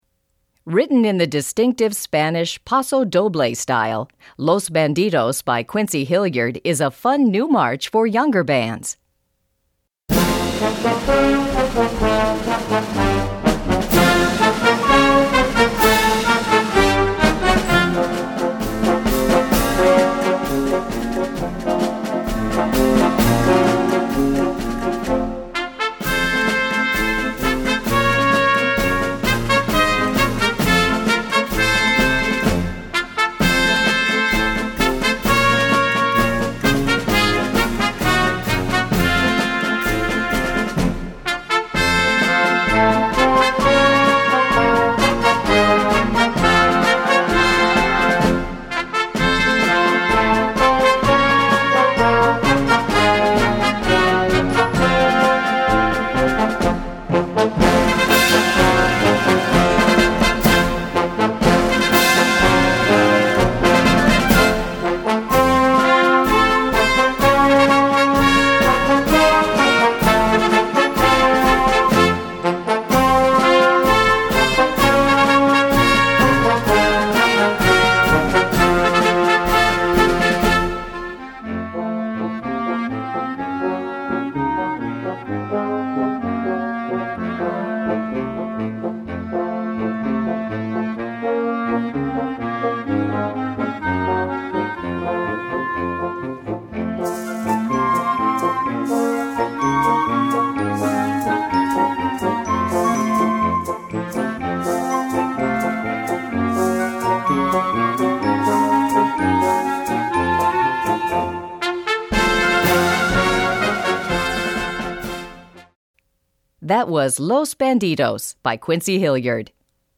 Gattung: Paso doble für Jugendblasorchester
Besetzung: Blasorchester